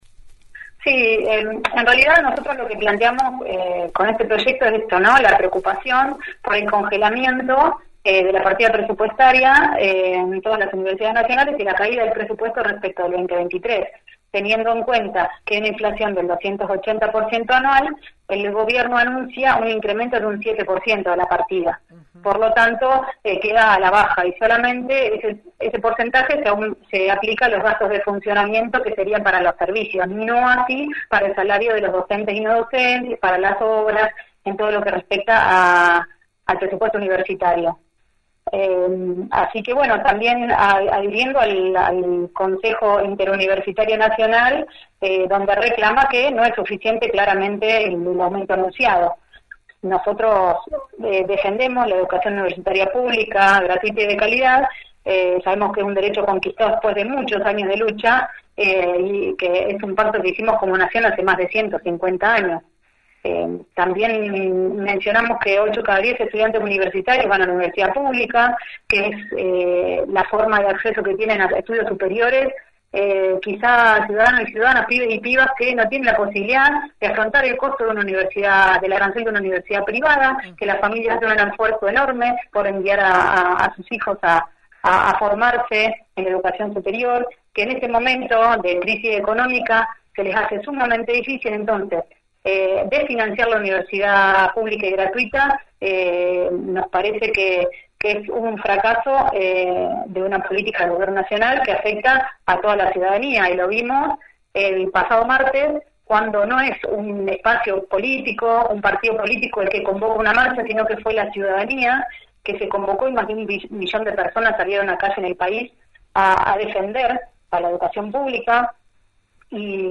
Luego de que vecinos mostraran esta semana en las redes sociales sus recibos de luz con sumas exorbitantes, la concejal por el oficialismo habló en la mañana de FM Alpha.